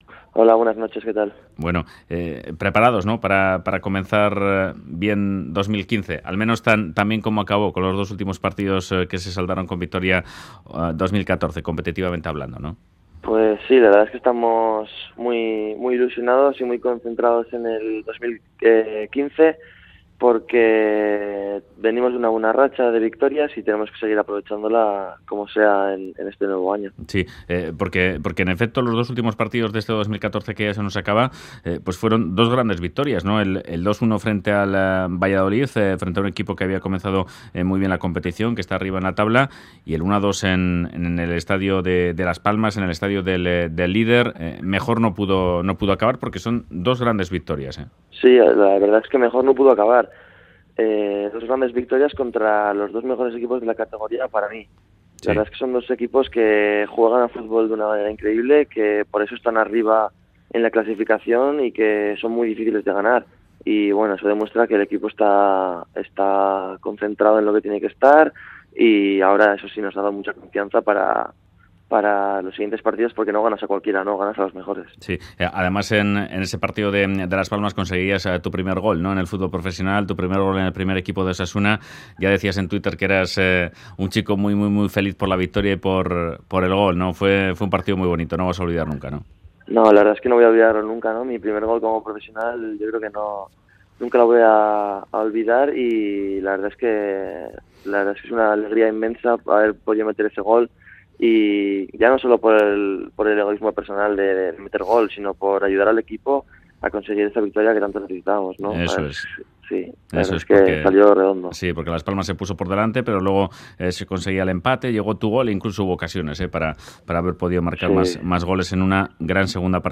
La joven promesa de Osasuna, Mikel Merino, se pasa por Fuera de Juego de radio euskadi para hablar de sus primeros partidos como futbolista profesional